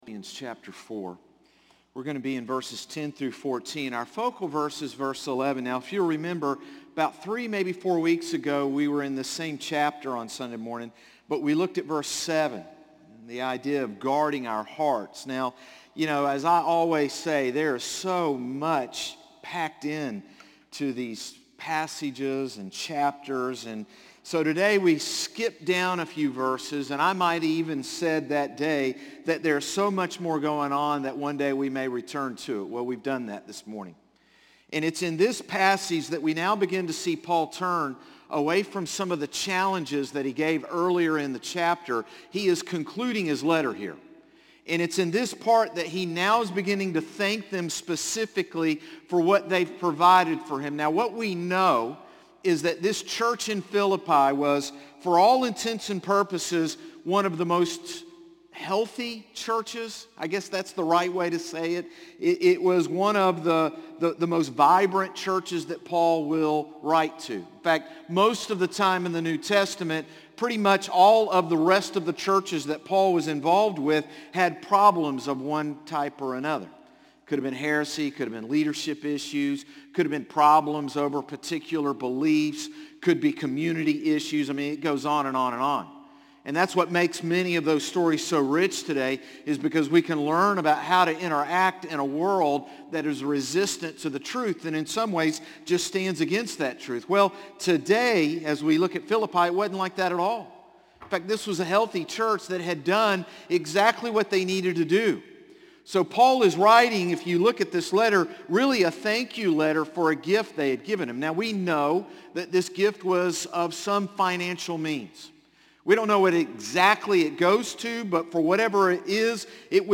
Sermons - Concord Baptist Church